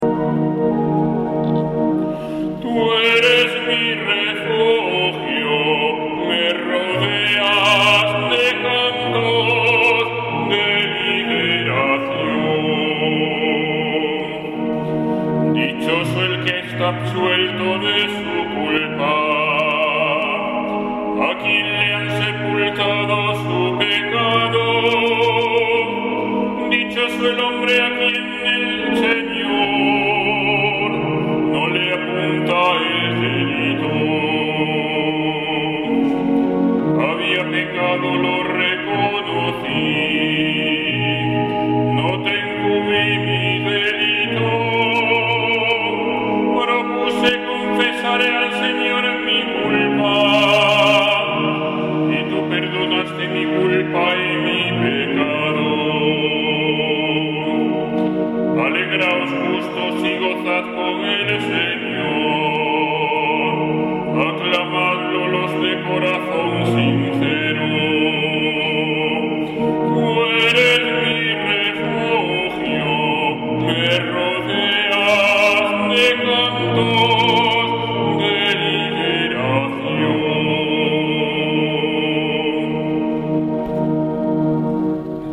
Salmo Responsorial 31/ 1-2; 5; 11